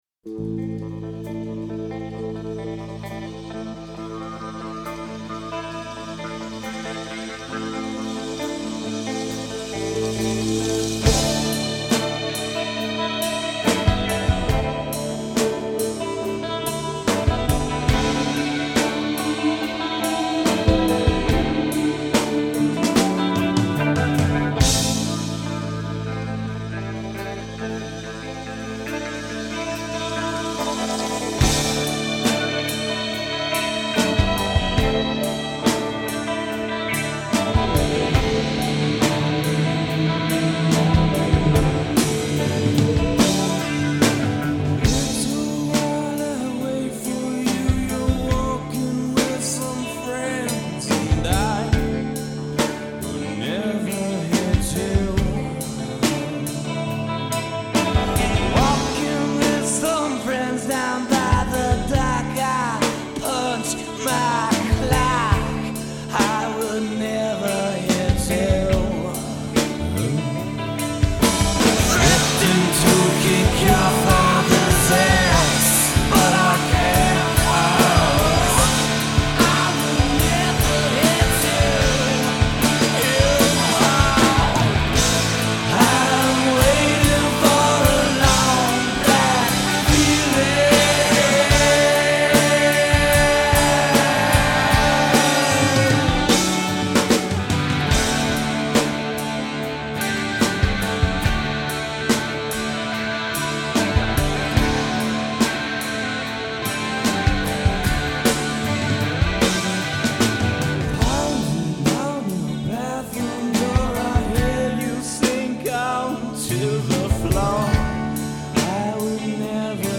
LIVE IN 09!